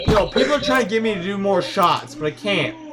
shots